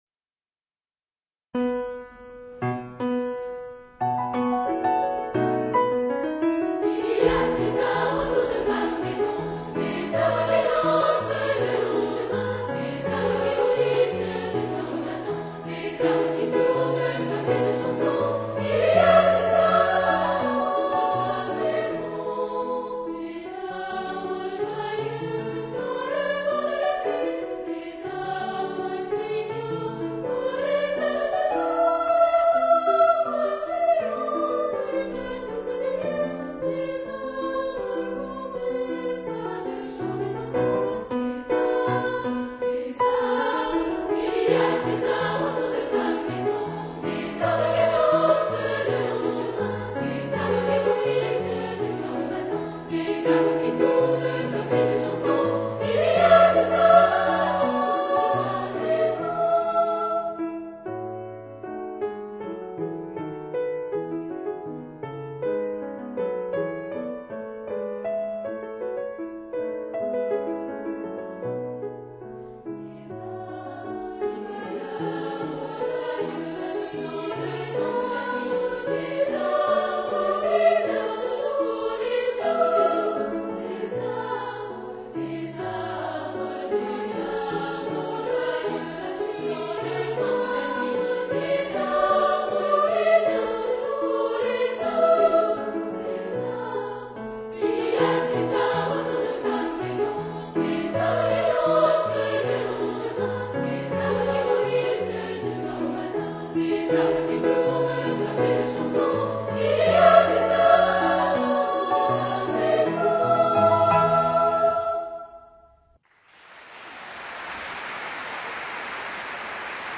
Género/Estilo/Forma: Moderno ; Canción ; Profano
Instrumentos: Piano (1)
Tonalidad : mi mayor